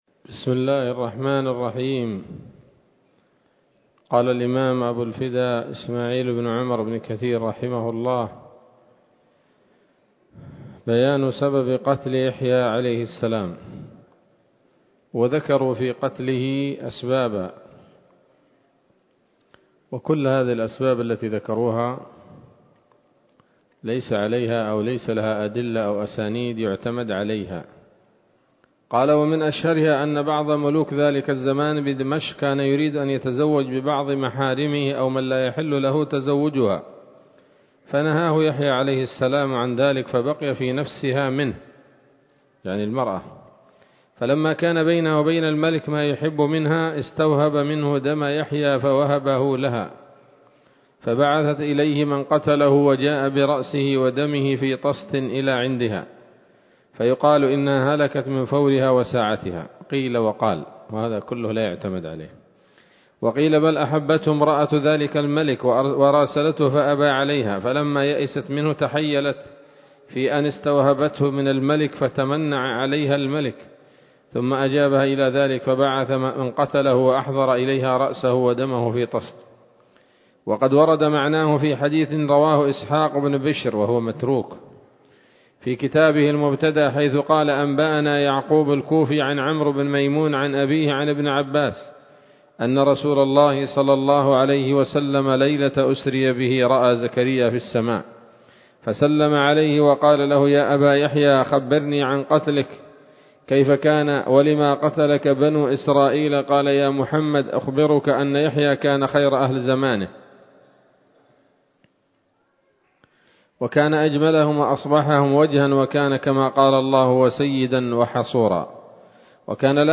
‌‌الدرس السادس والثلاثون بعد المائة من قصص الأنبياء لابن كثير رحمه الله تعالى